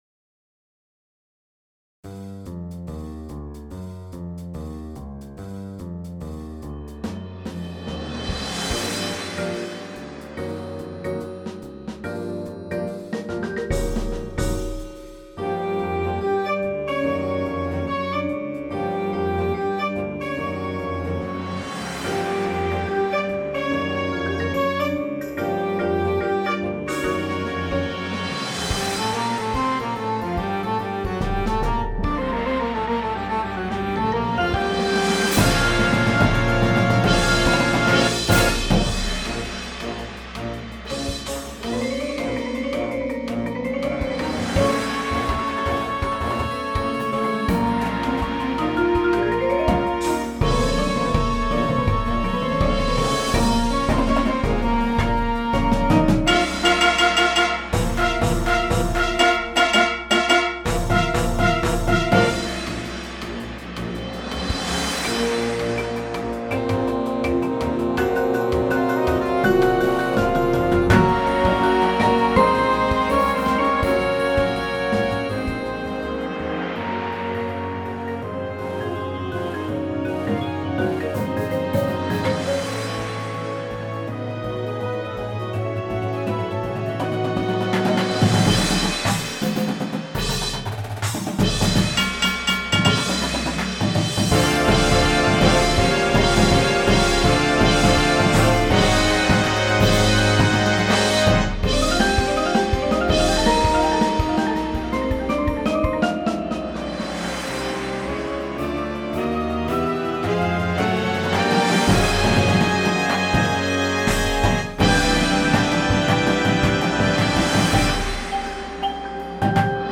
• Flute
• Trombone 1, 2
• Tuba
• Snare Drum
• Bass Drums